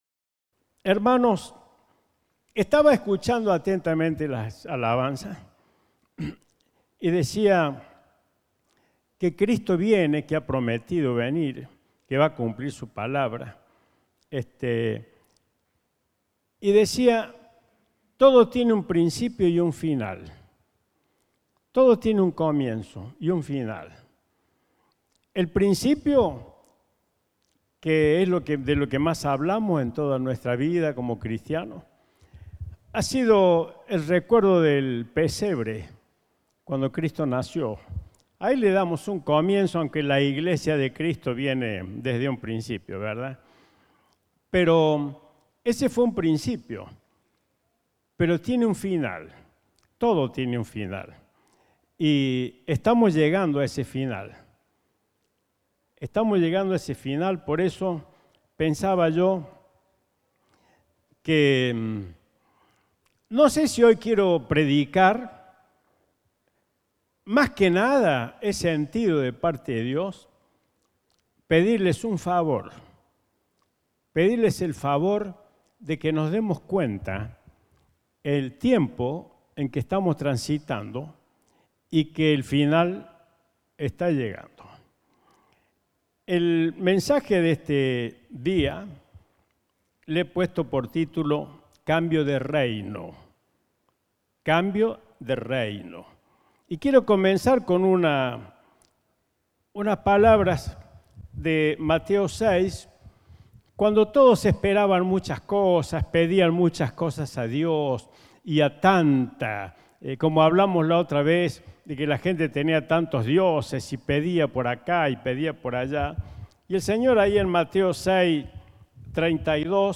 Compartimos el mensaje del Domingo 14 de Febrero de 2021